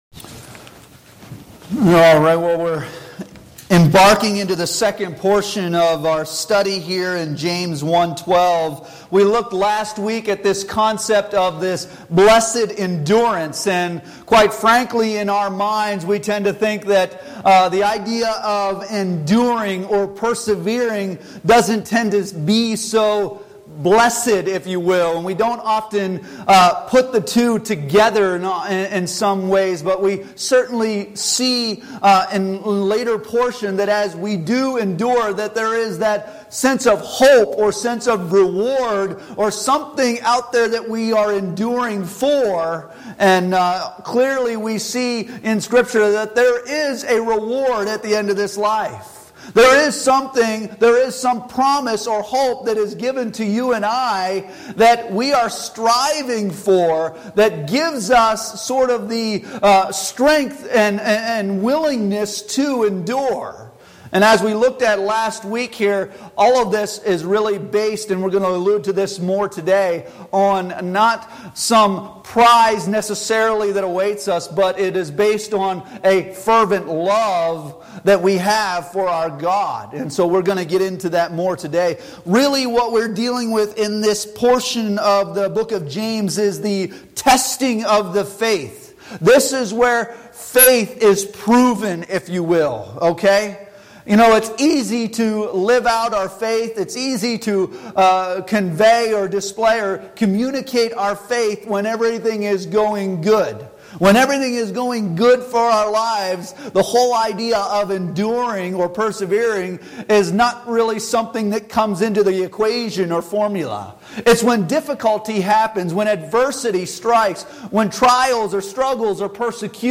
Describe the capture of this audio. James 1:12 Service Type: Sunday Morning Worship Bible Text